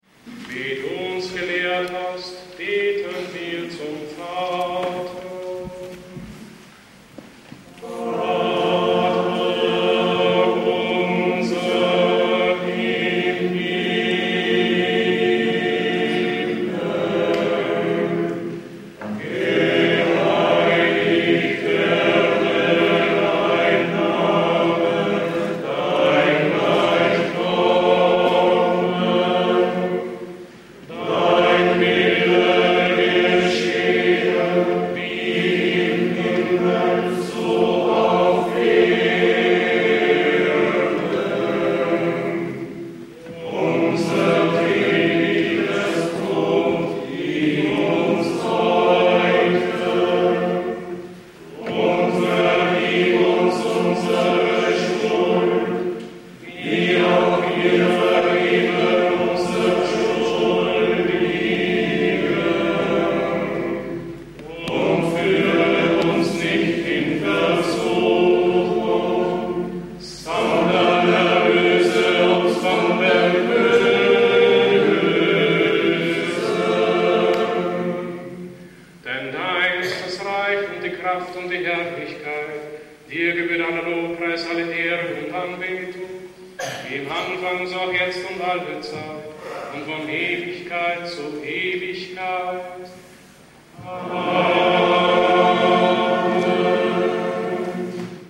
Für den Gebetsrahmen wählte der Gründer wegen seiner Ausdrucksstärke und seiner Erlernbarkeit den Kiewer Choral, wie er der liturgischen Praxis im bayerischen Kloster Niederaltaich (byzantinische Dekanie) zu Grunde liegt, hier mit strenger Terzparallele und Funktionsbass.